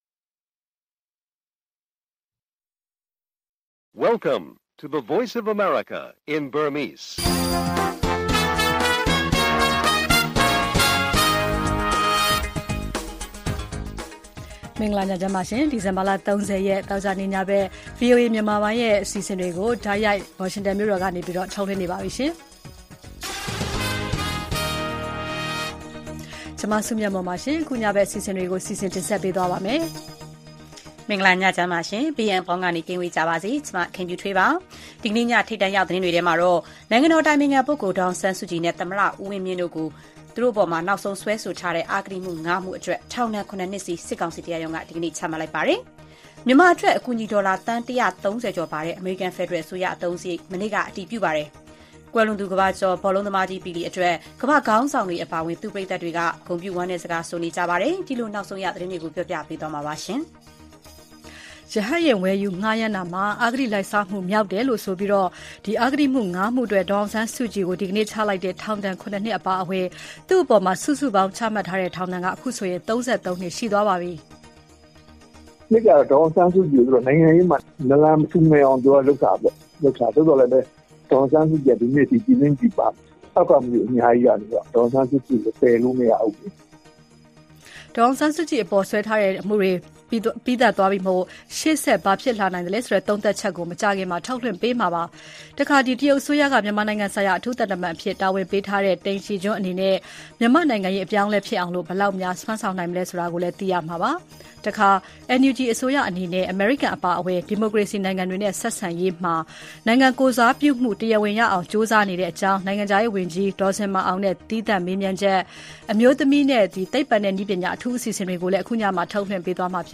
အမှု ၁၉ မှုအတွက် ထောင်ဒဏ် ၃၃ နှစ်နဲ့ ဒေါ်အောင်ဆန်းစုကြည်၊ မြန်မာ့နိုင်ငံရေးပြောင်းလဲအောင် တရုတ်အထူးကိုယ်စားလှယ်သစ် ဘယ်လောက်စွမ်းနိုင်မလဲသတင်းတွေနဲ့ NUG နိုင်ငံခြားရေးဝန်ကြီးဒေါ်ဇင်မာအောင်ရဲ့ သံတမန်ရေးကြိုးပမ်းမှု ဆက်သွယ်မေးမြန်းချက်၊ အမျိုးသမီးကဏ္ဍ၊ သိပ္ပံနဲ့နည်းပညာနှစ်ချုပ်အထူးအစီအစဉ်တွေကို တင်ဆက်ထားပါတယ်။